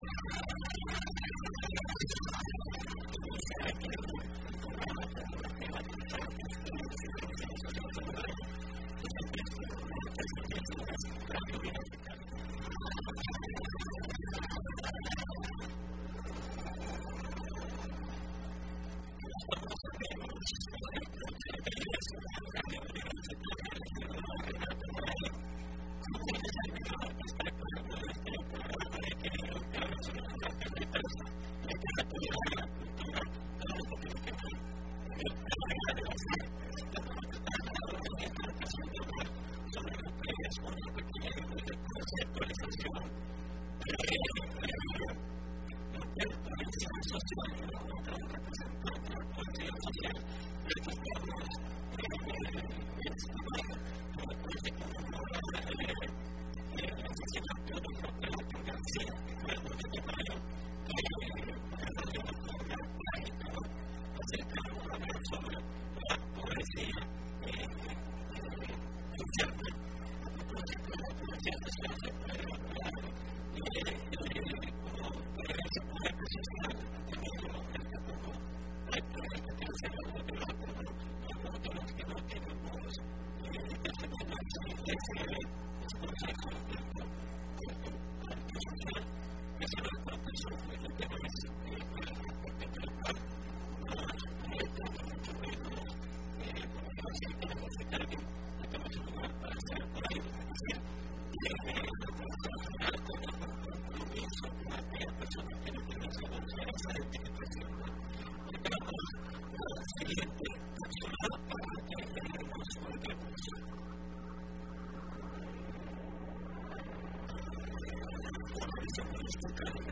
Entrevista programa Aequilibrium (05 mayo 2015): El poeta Roque Dalton como exponente de la Generación comprometida y las expresiones artísticas en torno a este personaje de las letras salvadoreñas